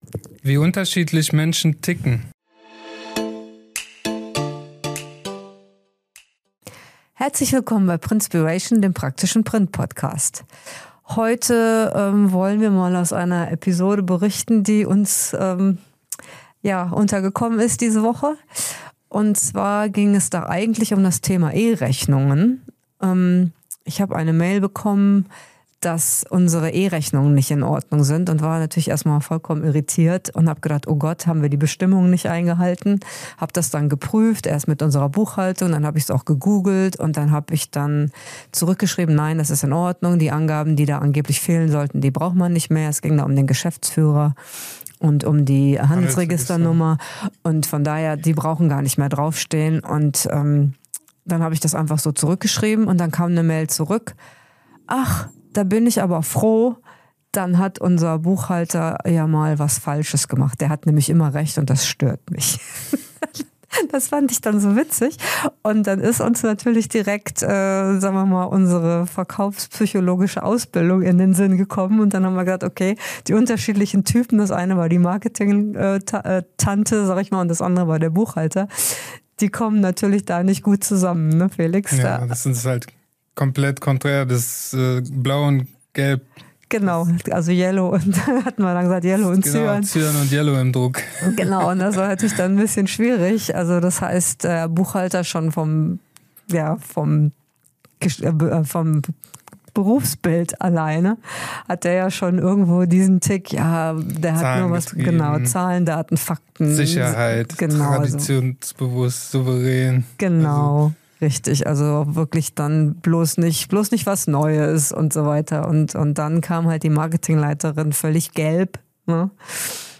Aus einer E-Rechnungs-Mail wird ein Lehrstück über menschliche Motive: Blau (Sicherheit, Fakten) prallt auf Gelb (Kreativität, Individualität). In dieser Folge bekommst du klare Anhaltspunkte, wie du Rot, Blau, Gelb und Grün erkennst, welche Wörter wirken und wie du Texte, Pitches und interne Kommunikation typgerecht aufziehst. Mit echten Beispielen, schnellen Formulierungen - und einem kurzen Studioeinsatz unserer Hunde.